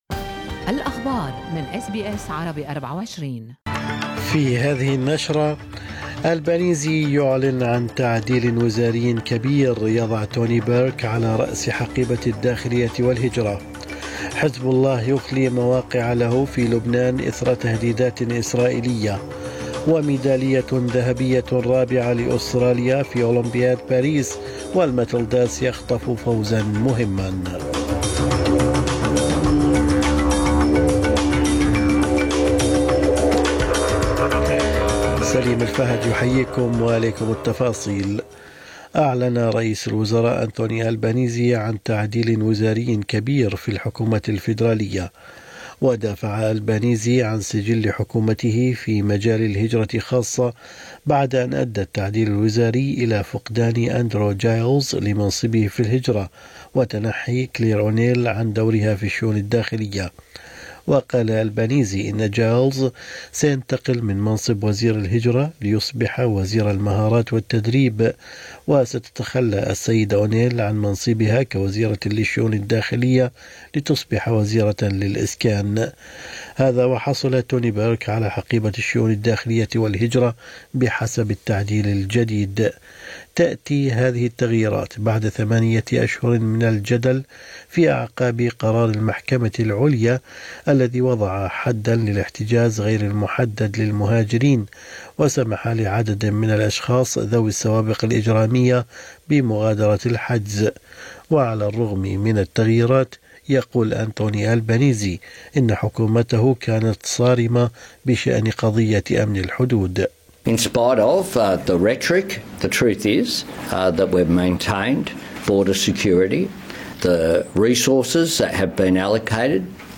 نشرة أخبار الصباح 29/7/2024